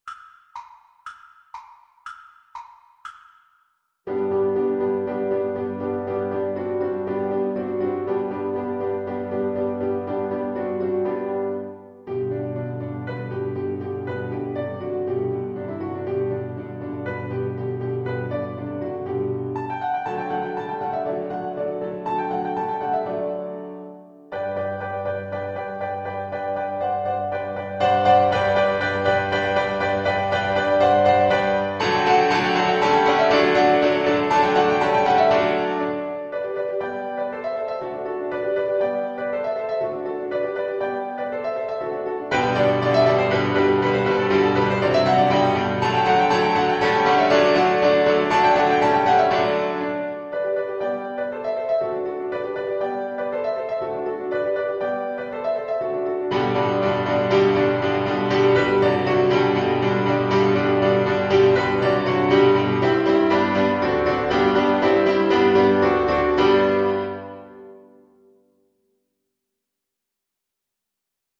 2/4 (View more 2/4 Music)
Classical (View more Classical Viola Music)